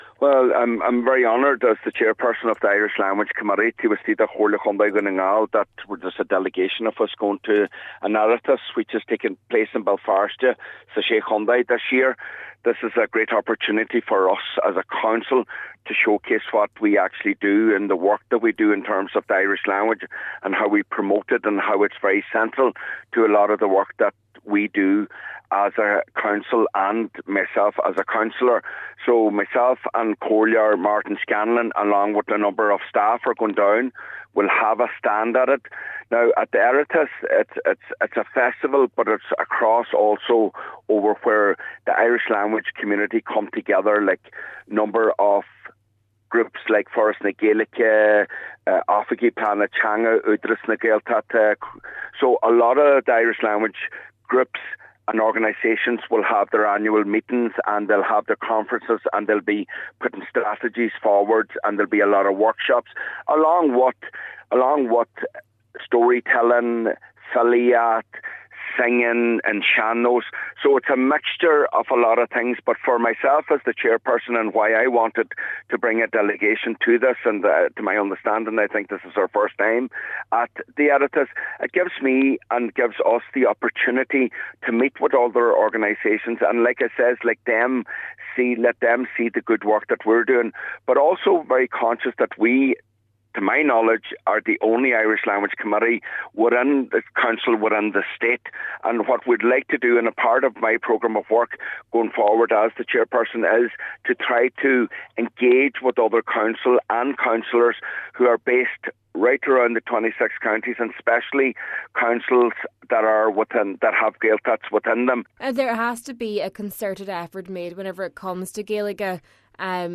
Cllr Mac Giolla Easbuig says events such as these play a key role in keeping the language alive: